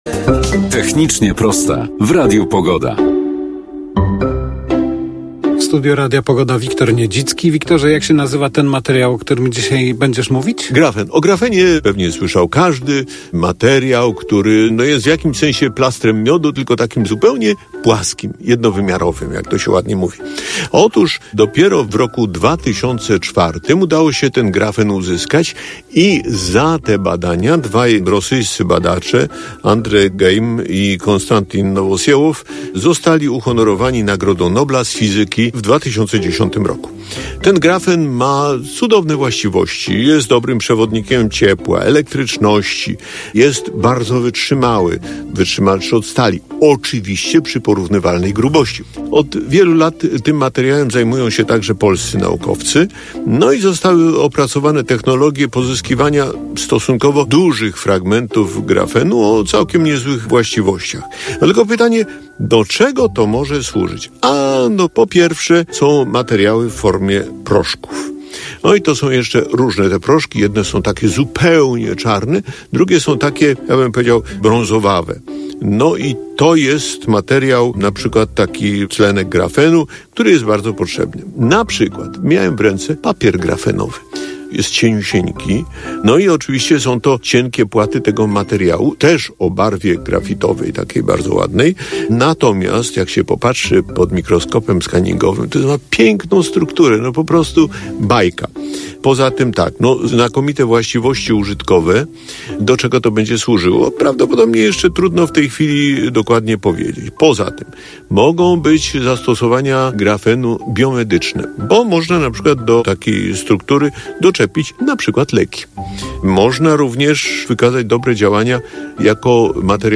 Zapraszamy do odsłuchania audycji radiowej "Technicznie proste", w której dziennikarz Wiktor Niedzicki omawia grafen, materiał o wyjątkowych właściwościach.